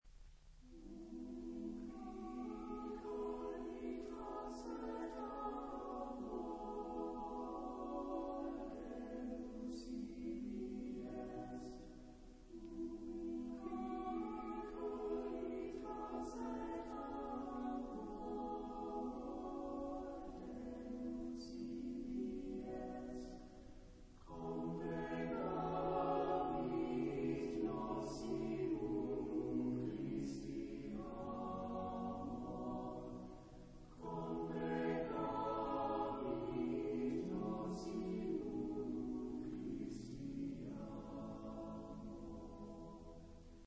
SAATTBB.
Motet. Sacred.